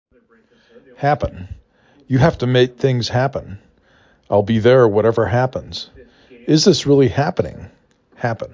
6 Letters, 2 Syllable
hap pen
h a p ə n